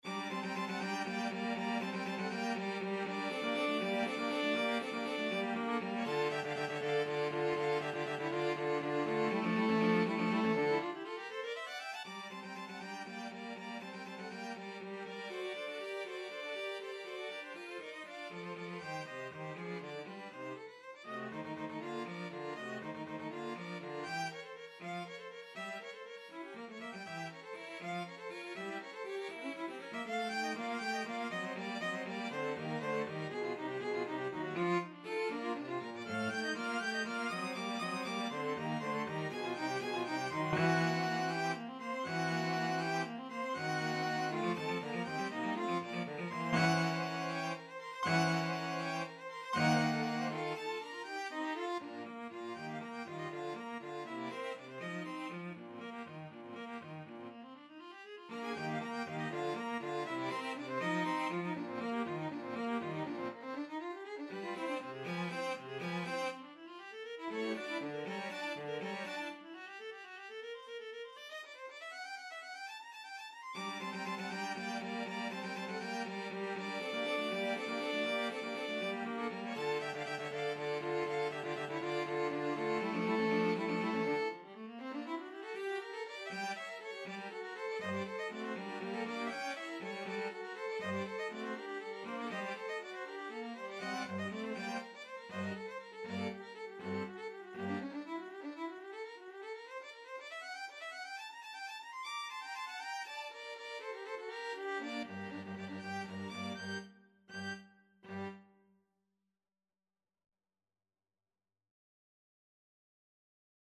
Violin 1Violin 2ViolaCello
3/8 (View more 3/8 Music)
. = 80 Allegro Molto Vivace (View more music marked Allegro)
String Quartet  (View more Advanced String Quartet Music)
Classical (View more Classical String Quartet Music)